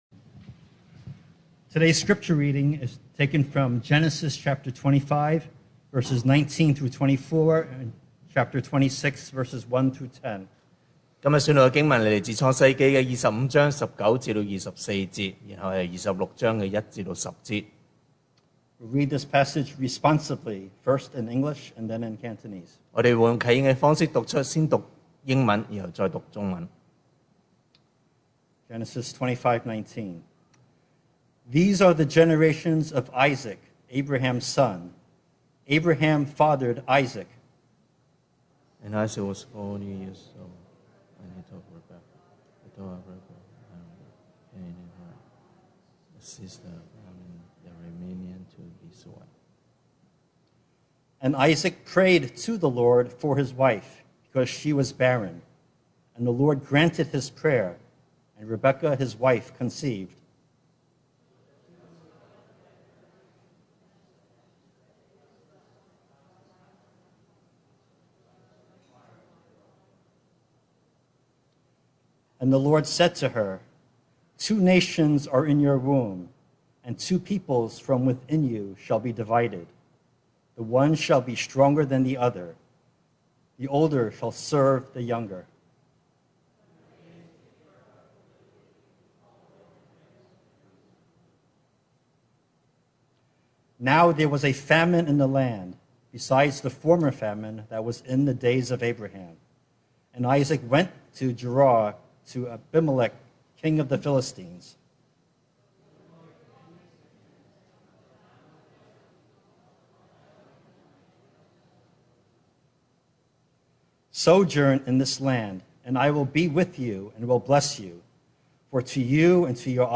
Series: 2022 sermon audios
Service Type: Sunday Morning